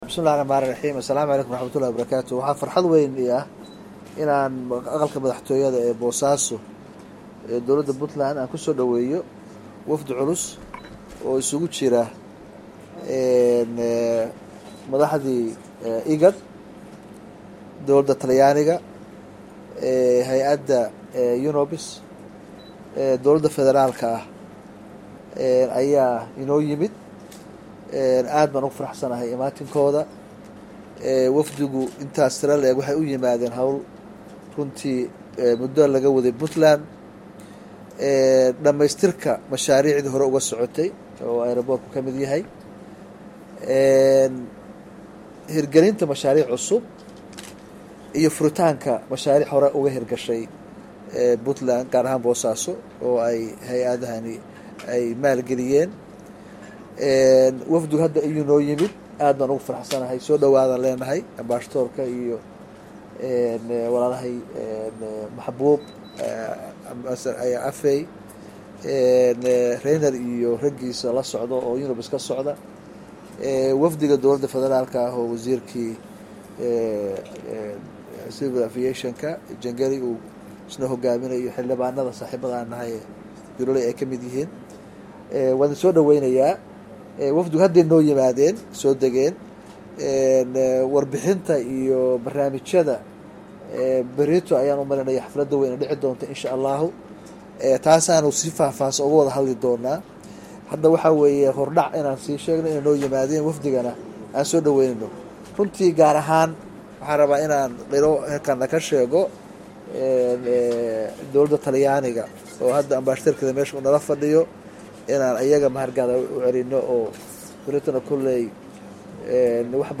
Dhagayso Madaxweynaga
Codka-Madaxweynaha-10.mp3